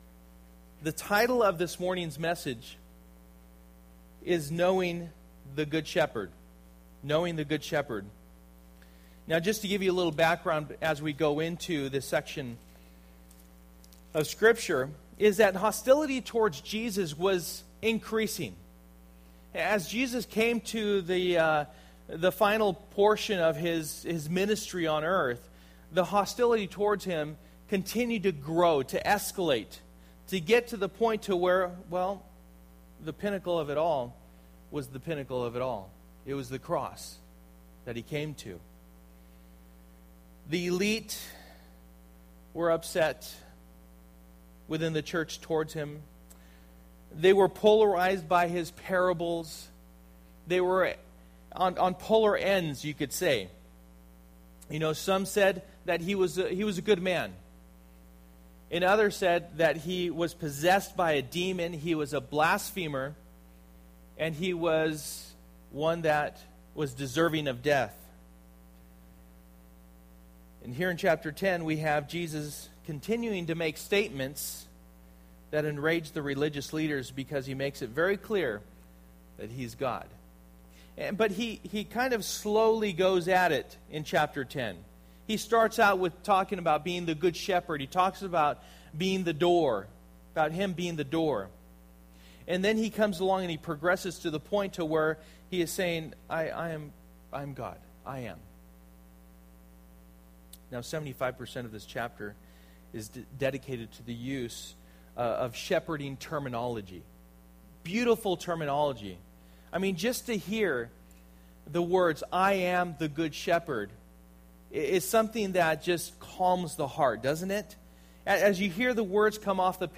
John 10:1-21 Service: Sunday Morning %todo_render% « One Blind